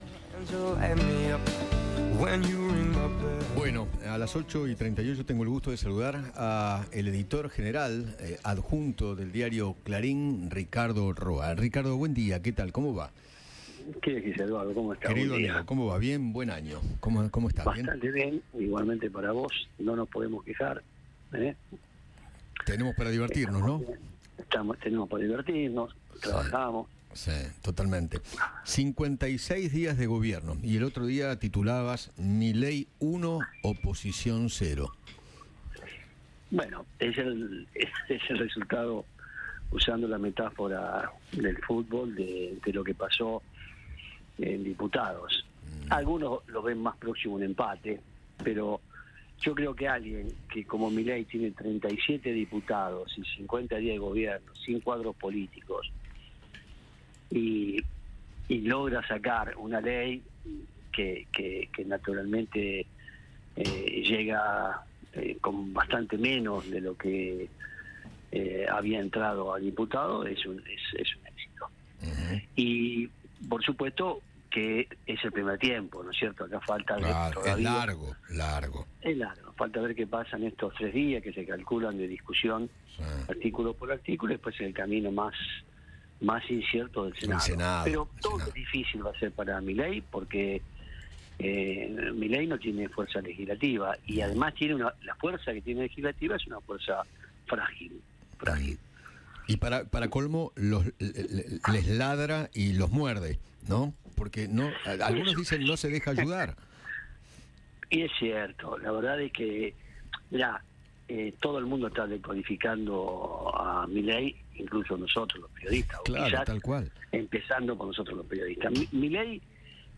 El periodista Ricardo Roa conversó con Eduardo Feinmann y analizó los primeros 56 días de gestión de Javier Milei.